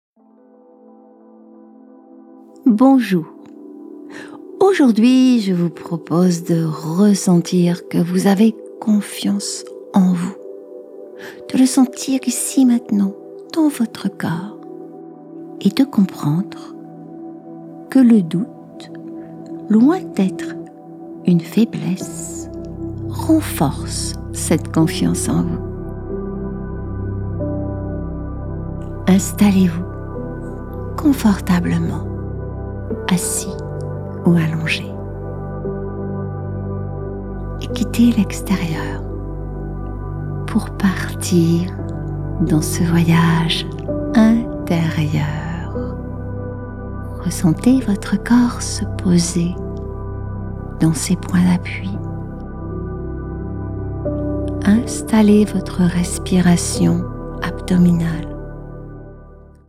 Relaxation guidée – Confiance en soi – Le doute
Cette séance de relaxation guidée peut se pratiquer aussi en journée.